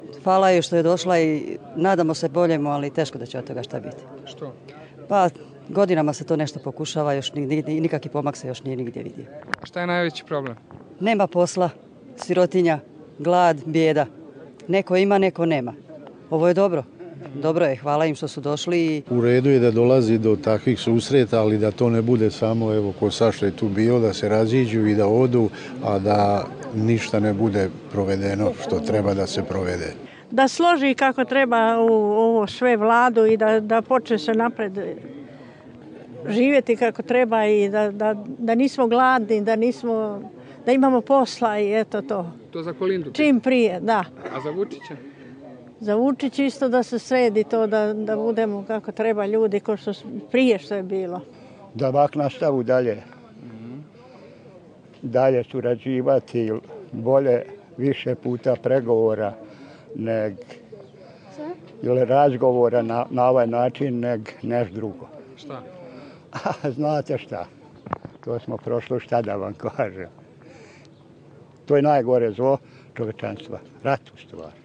Stavovi meštana